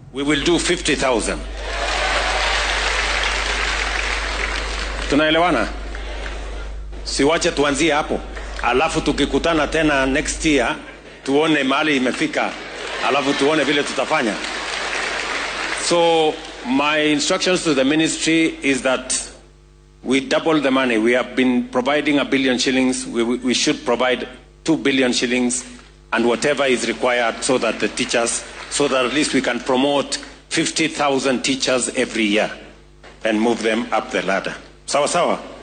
DHEGEYSO:Madaxweynaha dalka oo in ka badan 10,000 oo macalimiin ah ku qaabilay Aqalka Madaxtooyada ee Nairobi